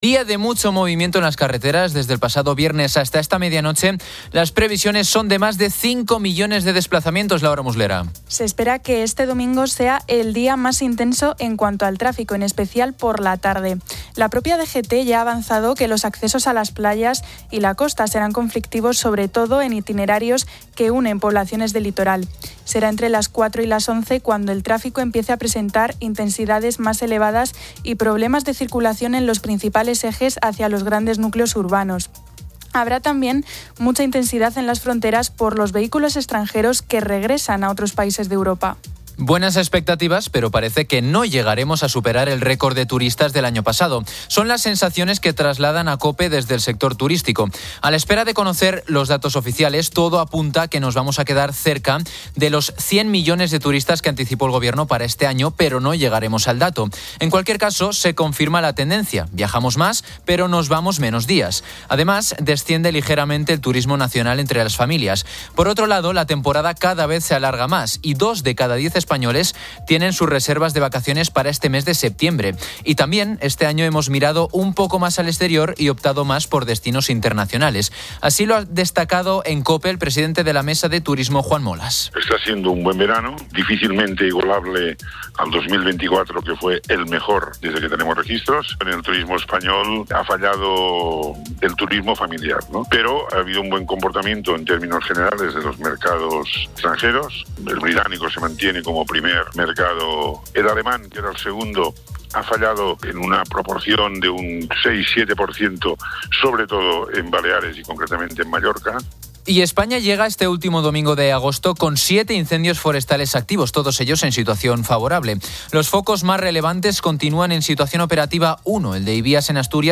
Fin de Semana 10:00H | 31 AGO 2025 | Fin de Semana Editorial de Cristina López Schlichting.